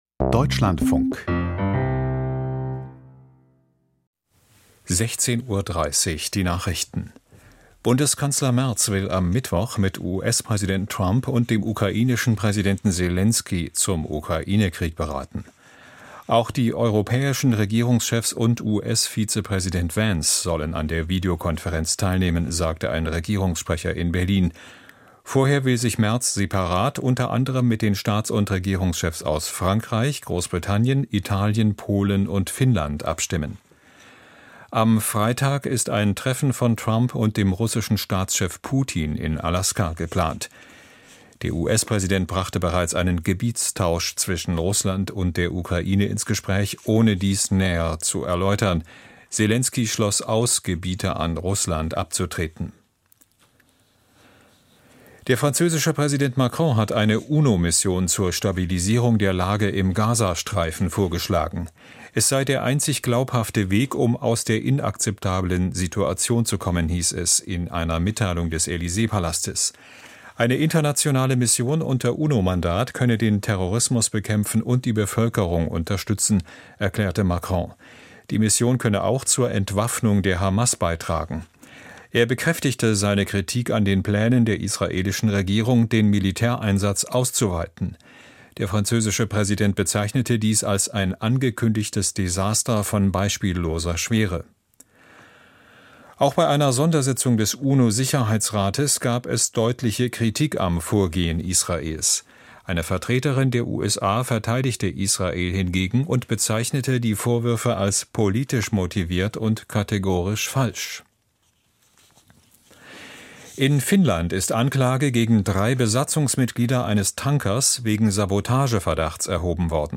Die Nachrichten vom 11.08.2025, 16:30 Uhr